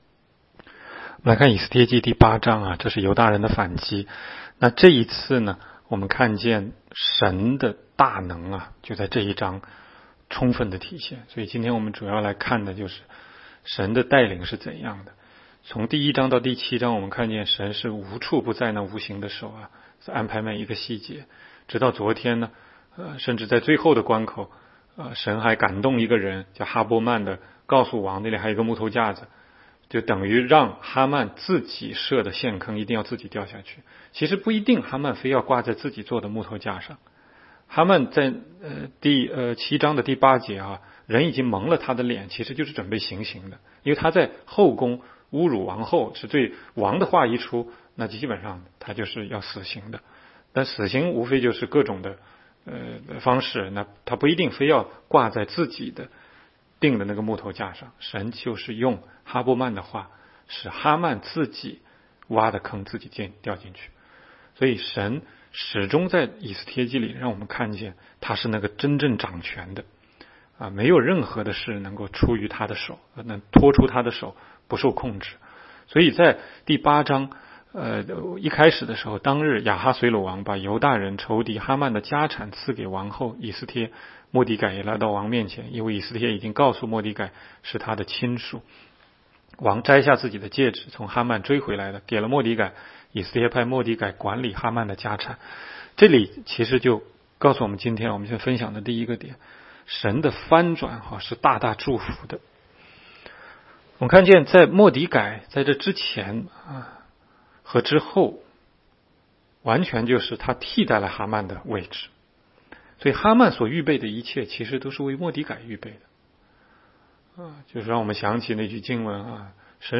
16街讲道录音 - 每日读经